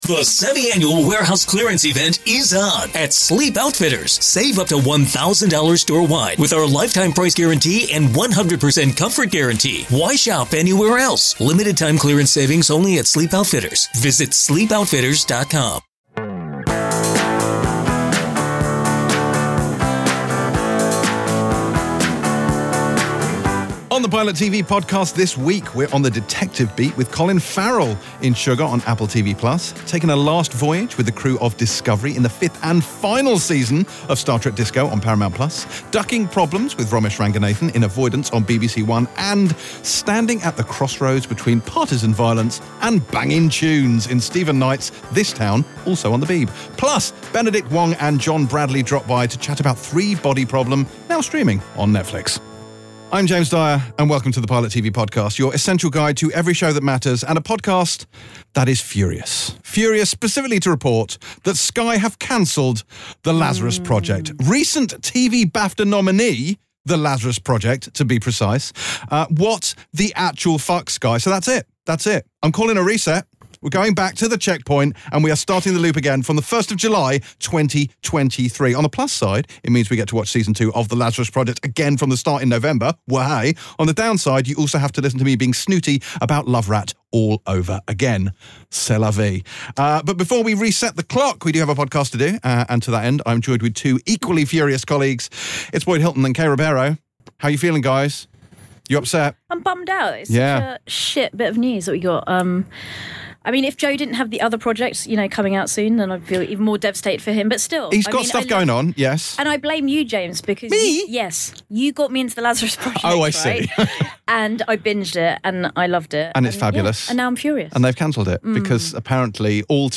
We’re back! it’s 2025 and to kick things off, James Norton and Niamh Algar join us to chat all things Playing Nice on ITV (27:24). But that’s not all, because ITV also brings us this week’s Wales-set drama Out There (1:02:07) with Martin Clunes.
Severance, Out There, and American Primeval. With guests James Norton and Niamh Algar